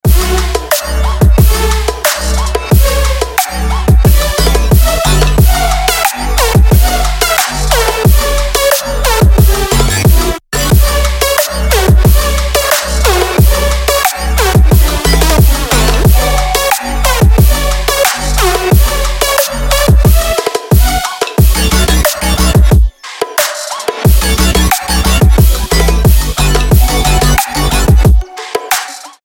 Electronic
без слов
future bass
Стиль: Future Bass